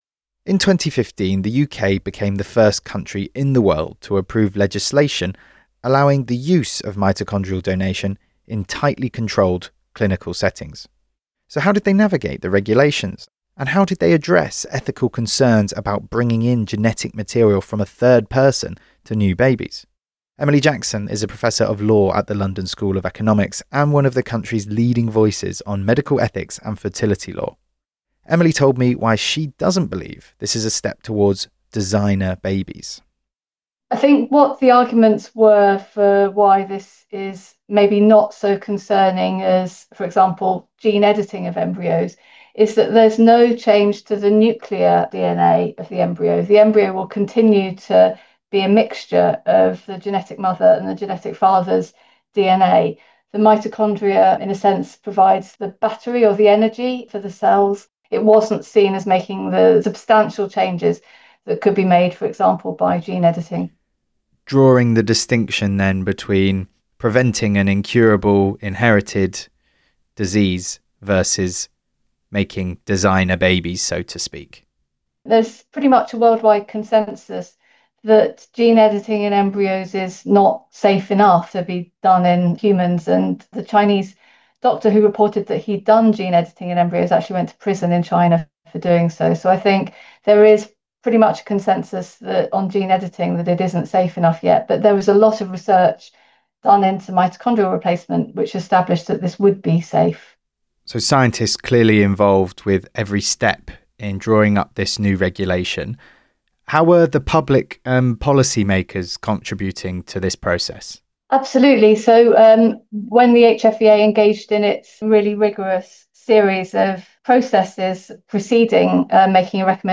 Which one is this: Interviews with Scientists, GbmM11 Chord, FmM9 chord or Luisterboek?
Interviews with Scientists